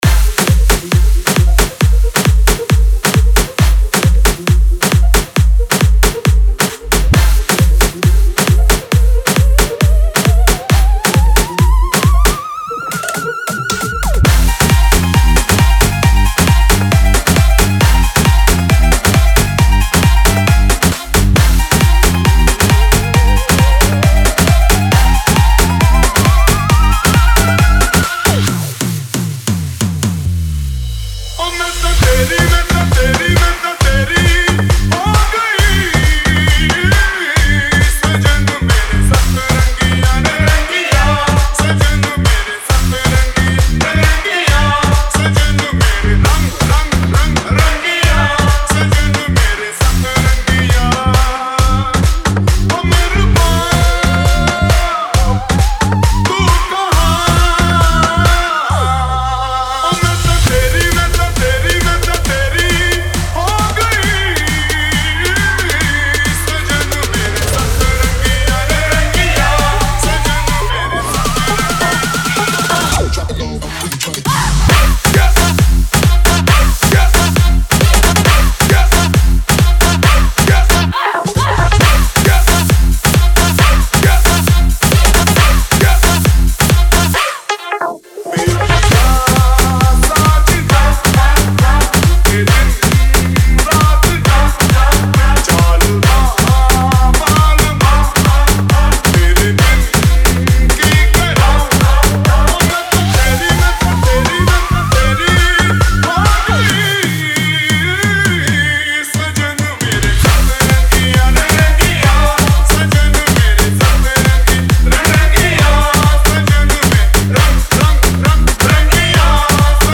Retro Single Remixes